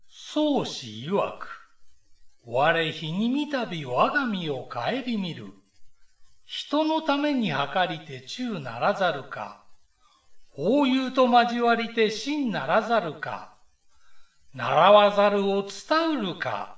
下の ＜朗読音声＞ をクリック又はタップすると、朗読音声が流れます。